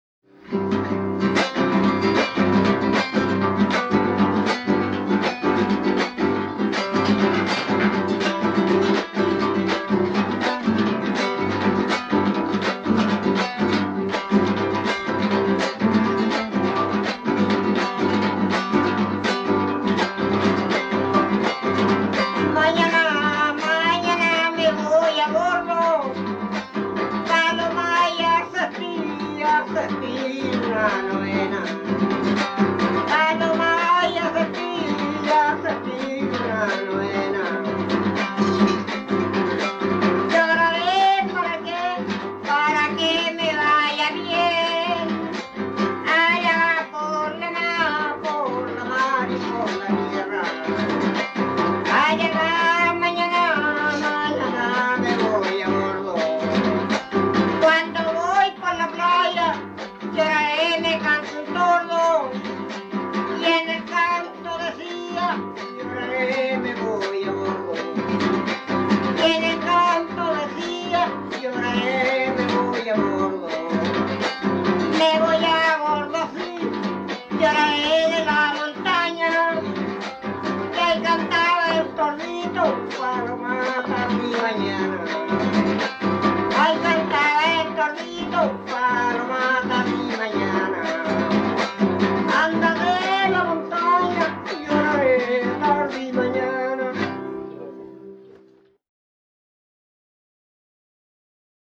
quien se acompaña con un guitarra afinada por solfa y cajón.
Música tradicional
Folklore
Cueca